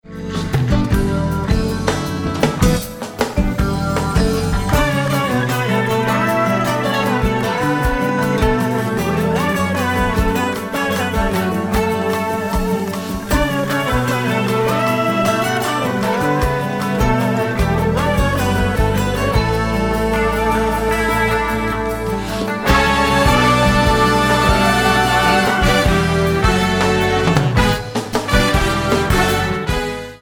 guitars: Taylor 712C (1980) acoustic guitar, Agostin Z24 Art